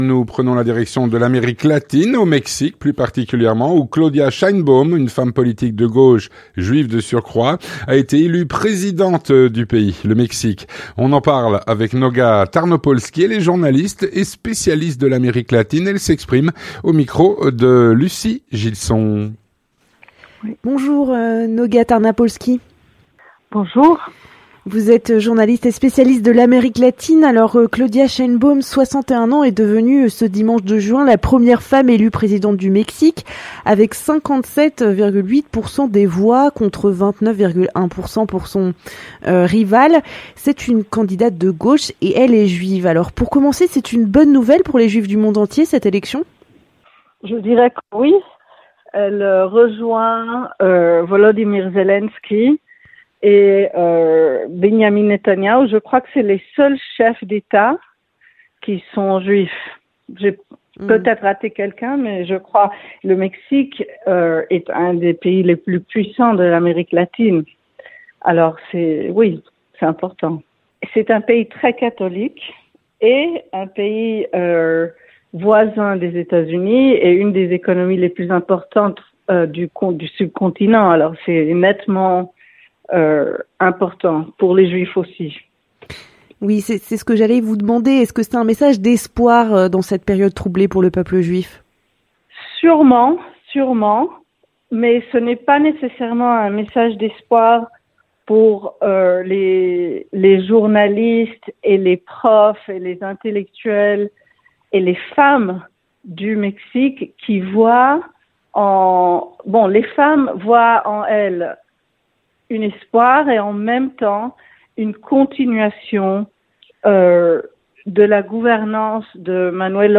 L'entretien du 18H - Claudia Sheinbaum, femme politique de gauche et juive, a été élue présidente du Mexique.